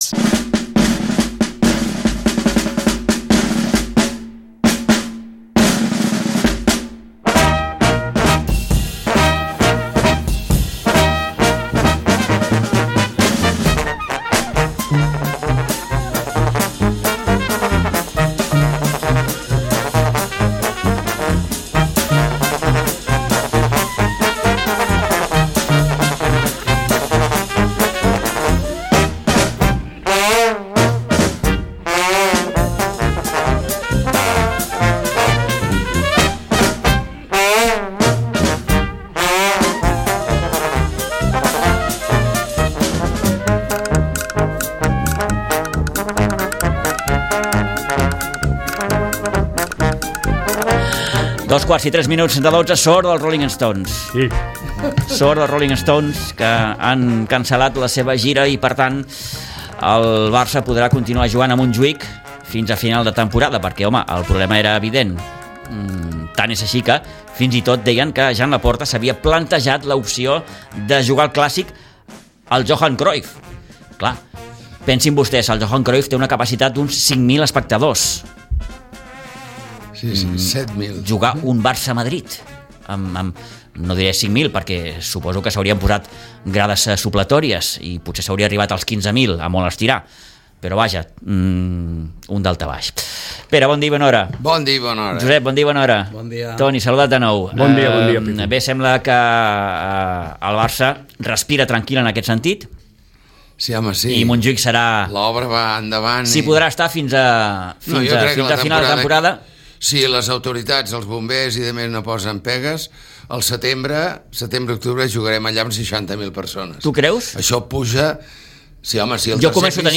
La tertúlia esportiva